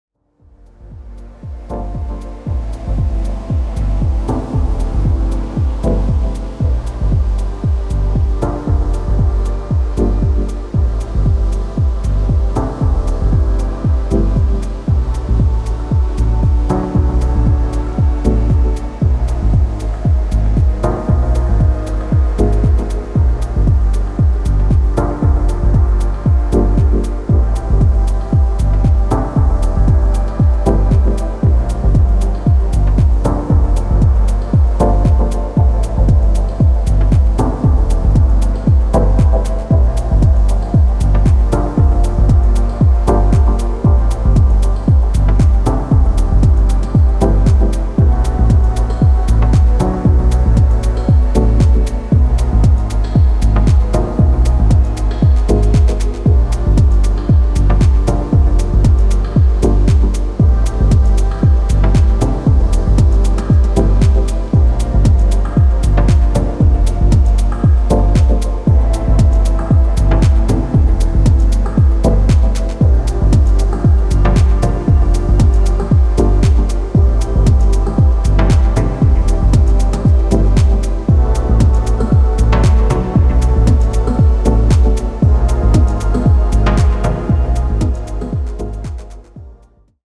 クールな温度感の中にドリーミーなフィーリングやメランコリックなテイストを織り込みながら